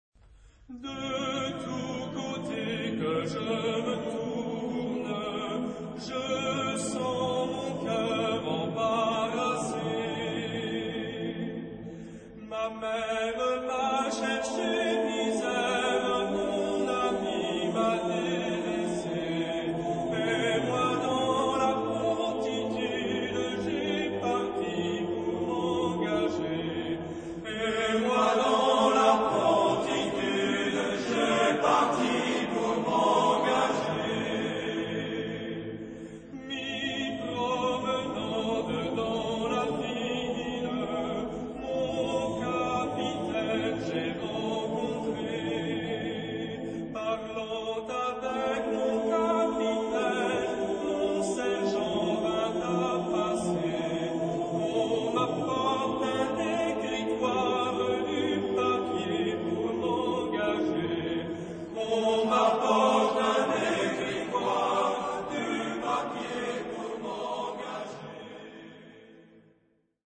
Dialogue entre un choeur d'hommes et un choeur de femmes ...
Genre-Style-Forme : Populaire ; Profane
Caractère de la pièce : vivant
Type de choeur : SSAATTBB  (8 voix mixtes )
Solistes : Soprano (1) / Ténor (1)  (2 soliste(s))
Tonalité : la mineur